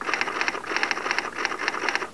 Typewriter 2 Sound Effect Free Download
Typewriter 2